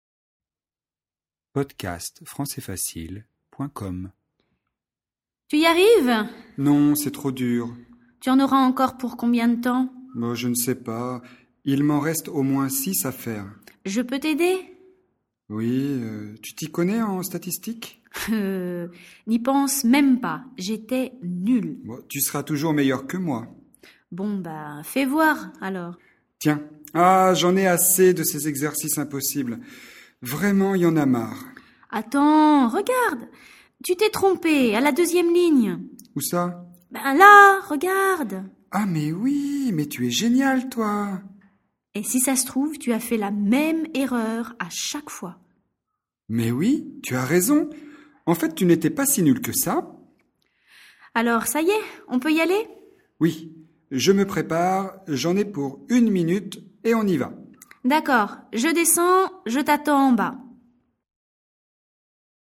Dialogue FLE, niveau intermédiaire (A2) sur l'utilisation des pronoms "EN" et "Y"